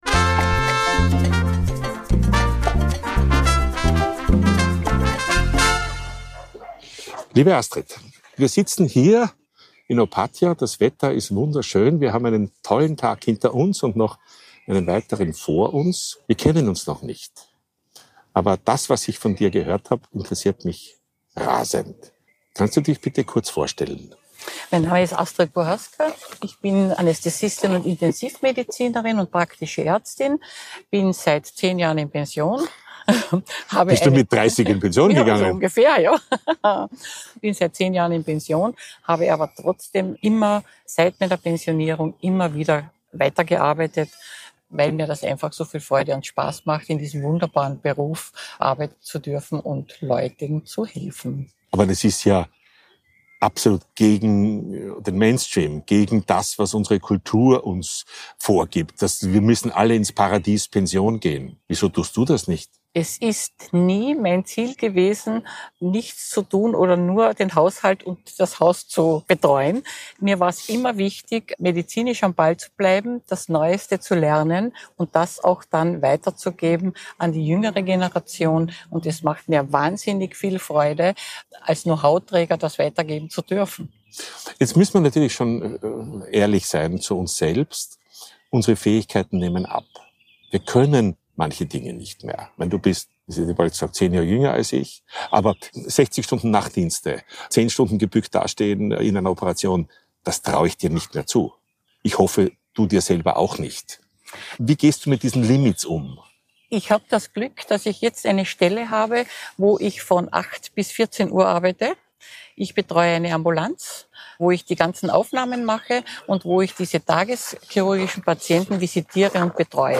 Ein Gespräch über Lebensphasen, medizinische Realität – und die große Kunst, dabei heiter zu bleiben.